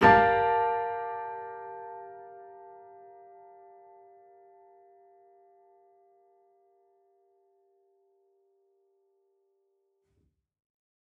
Index of /musicradar/gangster-sting-samples/Chord Hits/Piano
GS_PiChrd-Gmin6+9.wav